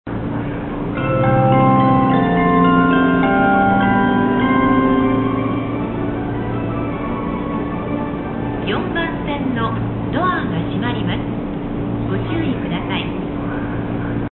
武蔵中原駅メロディ
通常の設定で可能な放送です。武蔵中原駅だけ「の」がつく。